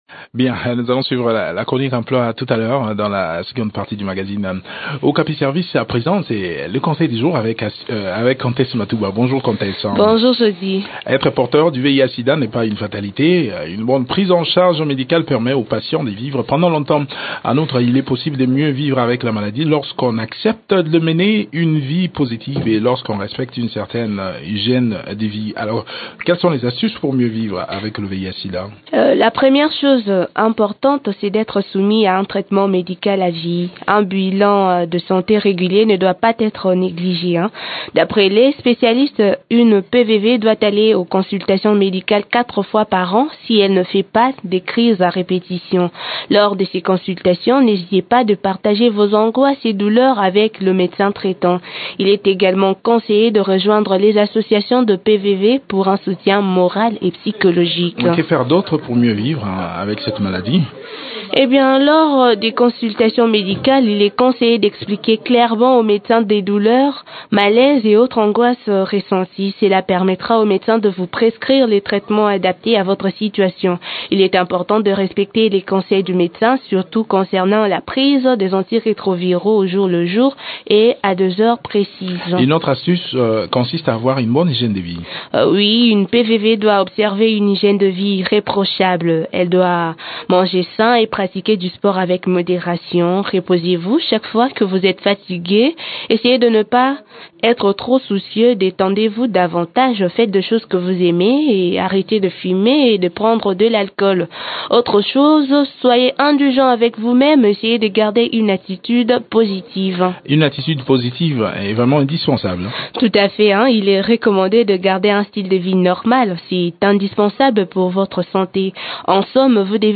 Découvrez des astuces dans cette chronique